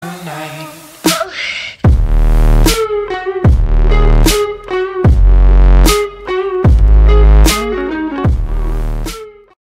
Play, download and share aaayy original sound button!!!!
twitch-follower-sound-go-fk-yourself-audiotrimmer_ujT980c.mp3